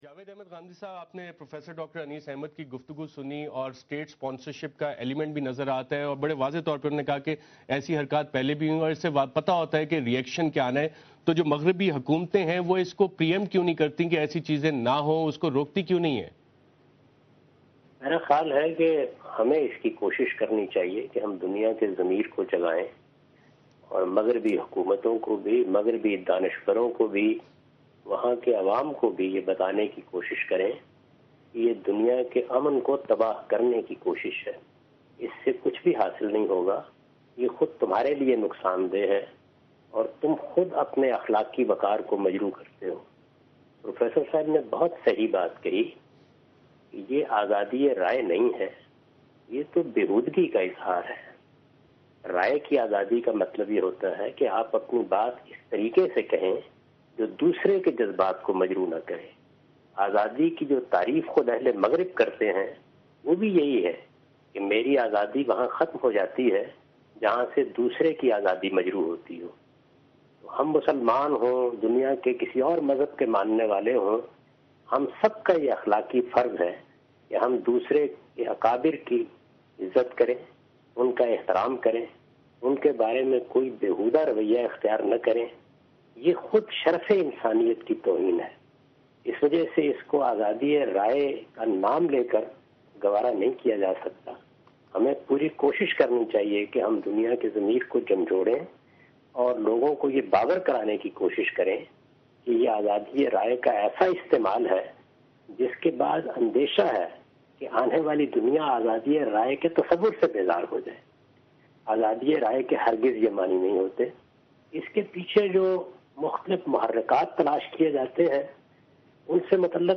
Category: TV Programs / Dunya News / Questions_Answers /